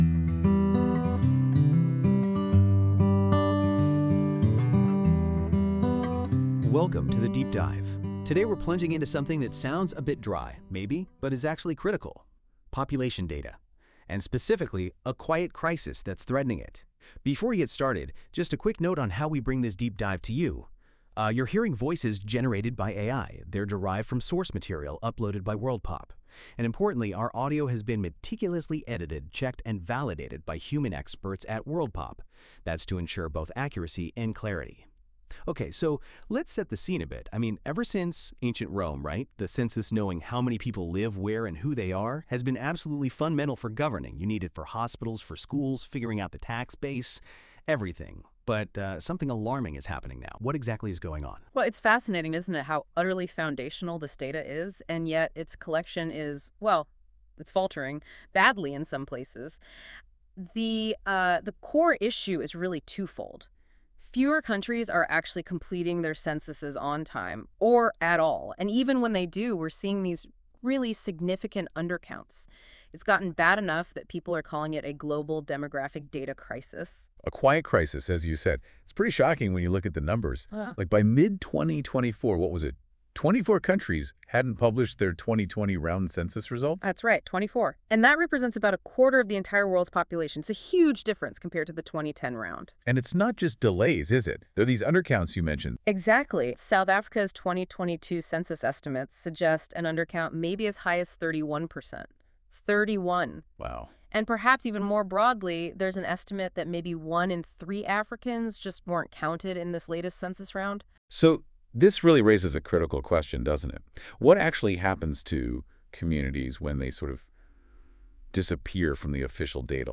This feature uses AI to create a podcast-like audio conversation between two AI-derived hosts that summarise key points of a document - in this case the Disappearing People article in Science.
As Google acknowledge that NotebookLM outputs may contain errors, we have been careful to check, edit and validate this audio.
Music: My Guitar, Lowtone Music, Free Music Archive (CC BY-NC-ND)